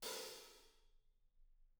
R_B Hi-Hat 07 - Room.wav